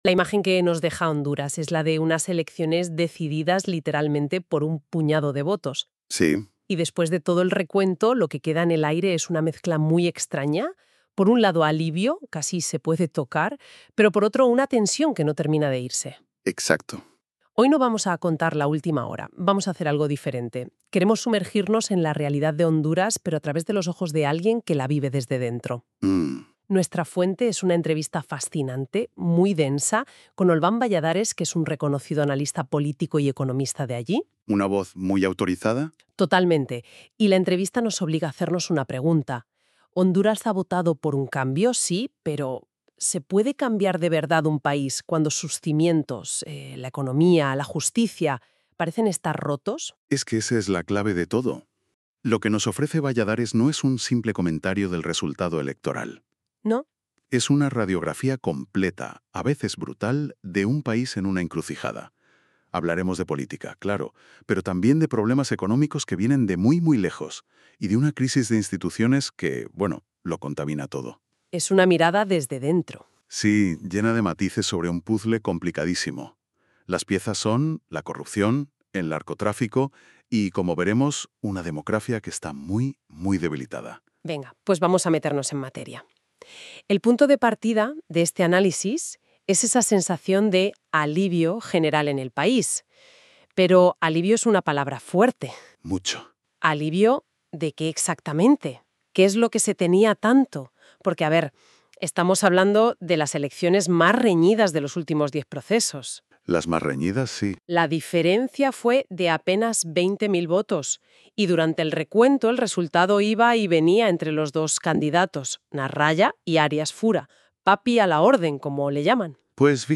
Entrevista y Podcast | Revista Panorámica